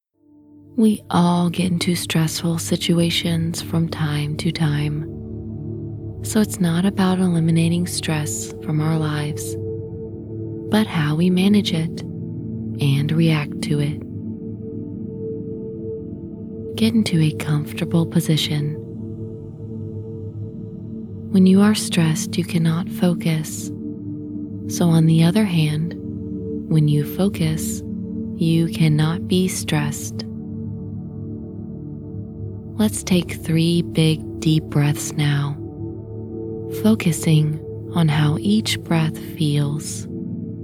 This audio session is ideal for anyone who want to increase and improve their gut feelings (intuitions) by using very powerful self hypnosis techniques to process complex situations in life better and improve your risk taking abilities.